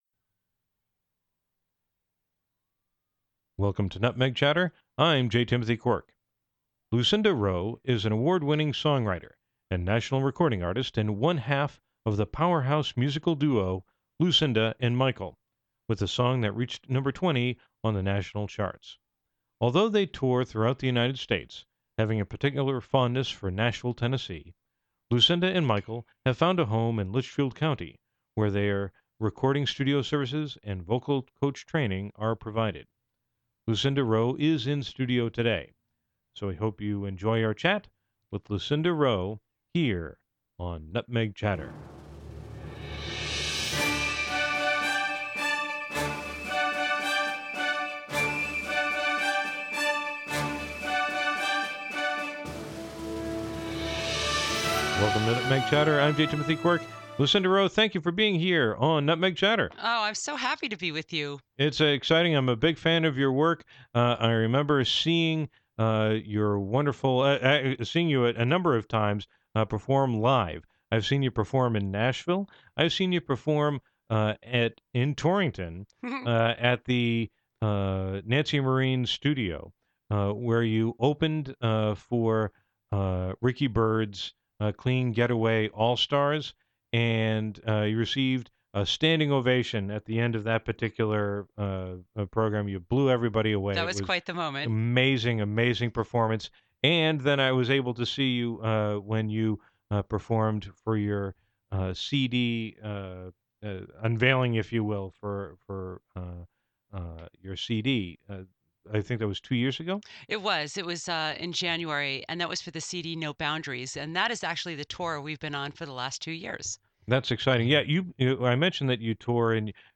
Categories: Radio Show, Torrington Stories